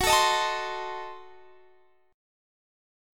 Listen to F#dim7 strummed